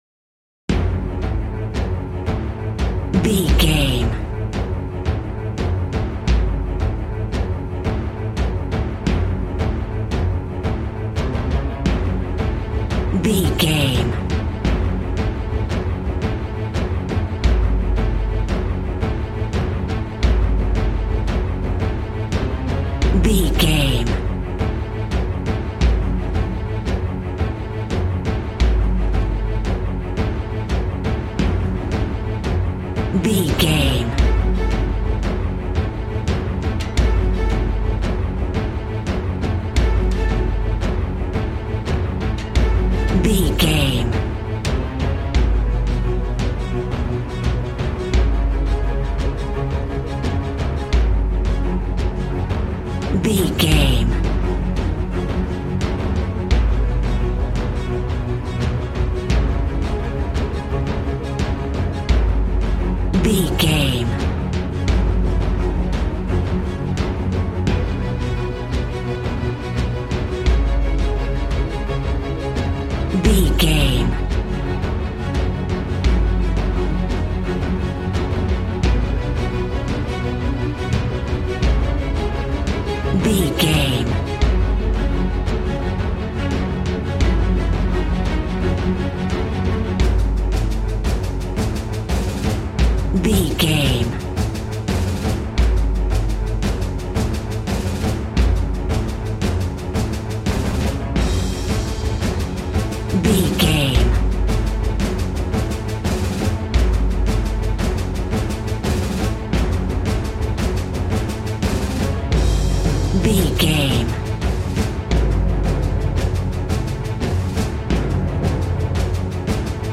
Epic / Action
Fast paced
In-crescendo
Thriller
Uplifting
Aeolian/Minor
G♭
dramatic
powerful
strings
brass
percussion
synthesiser